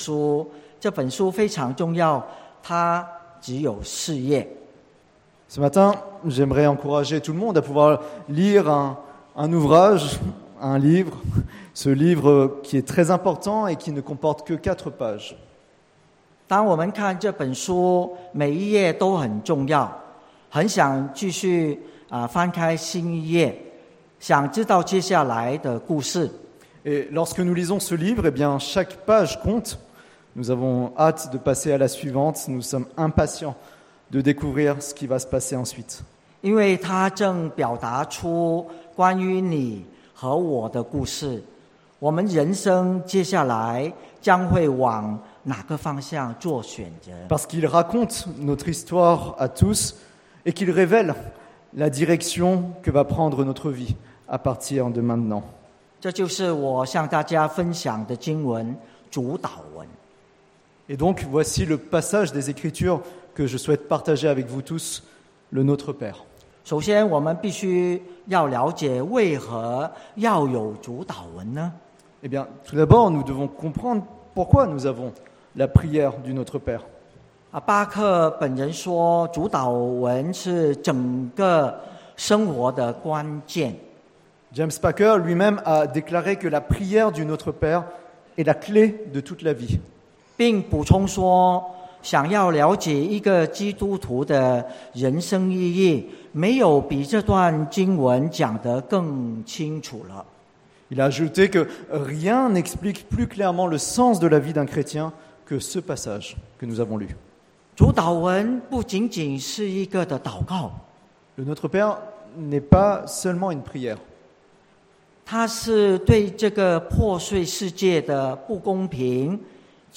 (chinois traduit en français)